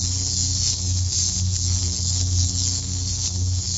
SFX_electricfence_AIF44khz.wav